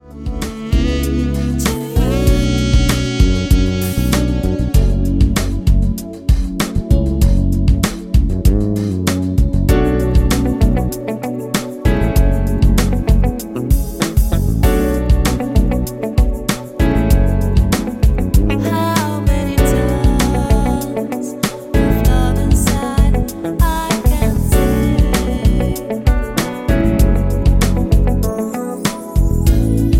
A#
MPEG 1 Layer 3 (Stereo)
Backing track Karaoke
Pop, 1980s